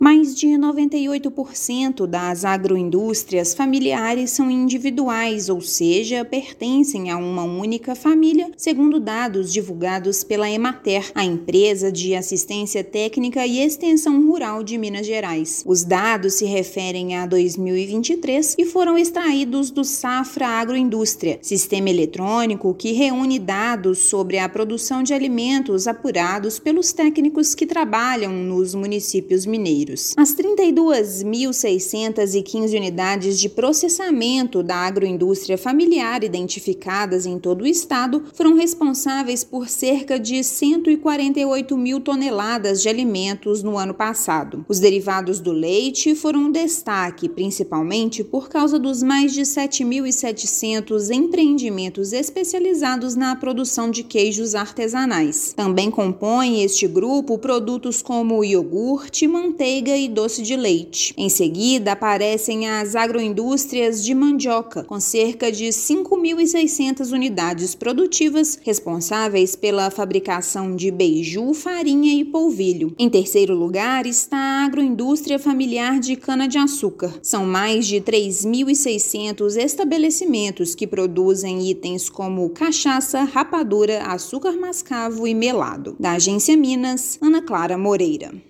Levantamento feito pela Emater-MG mostra que os estabelecimentos familiares produzem 146 mil toneladas de alimentos por ano. Ouça matéria de rádio.